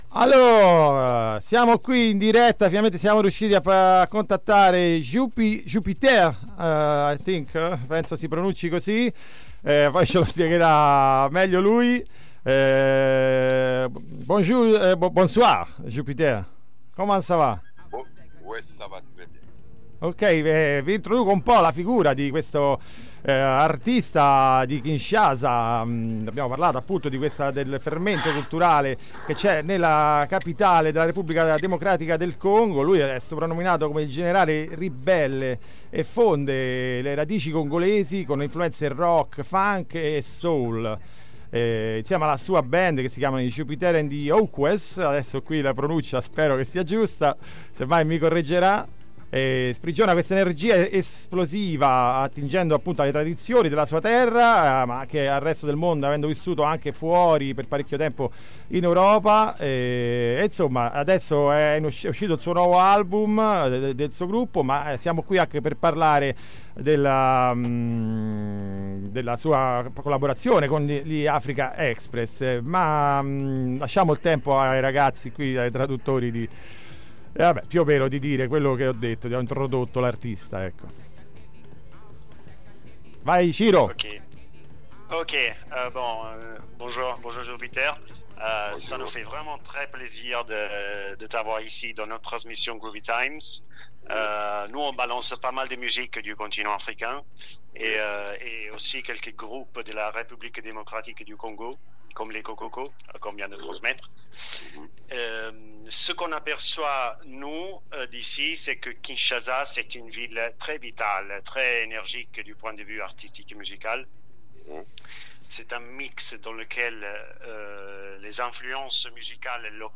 Intervista a Jean Pierre Bokondji, in arte Jupiter, dei Jupiter & The Okwess. Il gruppo nasce nei primi anni 2000 anni nella scena underground di Kinshasa creando un sound che è un insieme di funk, rock, afrobeat e ritmi tradizionali congolesi, un mix travolgente che loro stessi chiamano Bofenia Rock.
intervista-jupiter-and-the-okwess.mp3